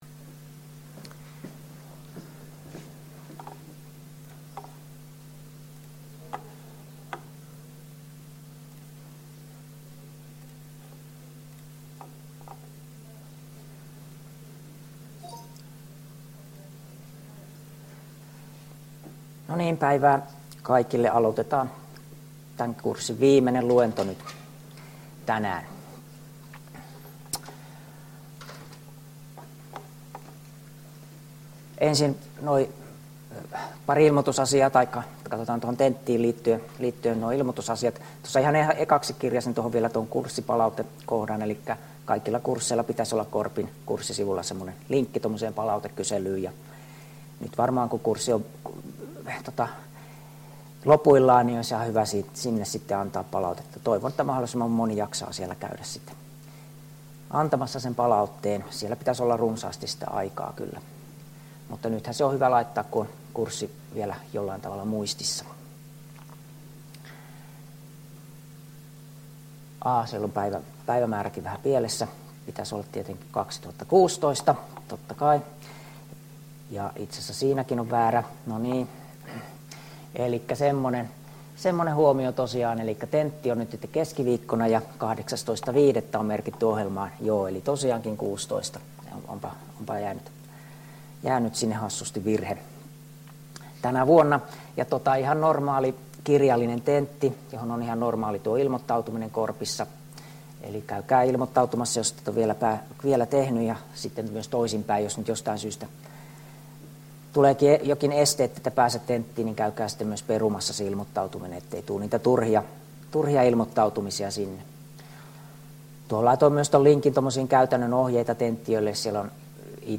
Luento 13 — Moniviestin